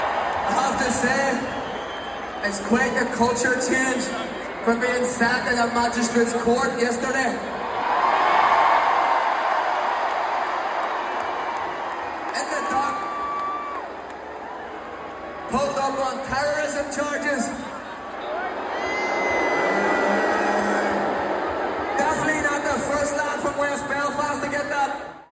The Belfast rap group played a sold out gig at Fairview Park last night.
He addressed the issue at last night’s gig……………..